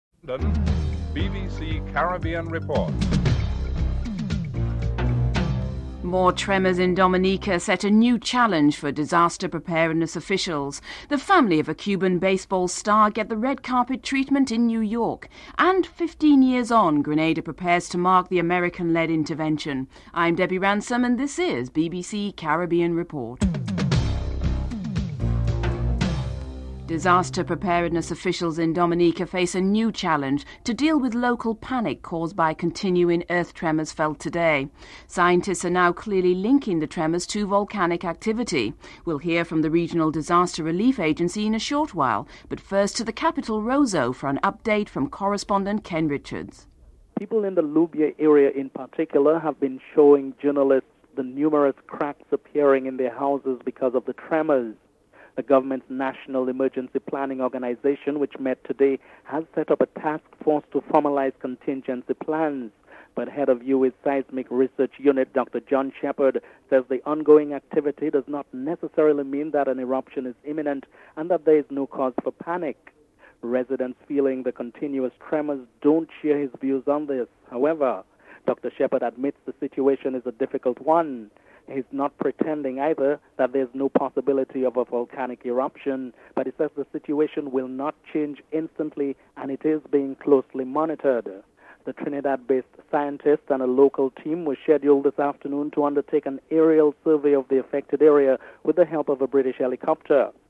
1. Headlines (00:00-00:25)